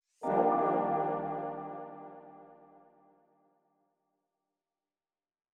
Added menu sounds
LAUNCH.wav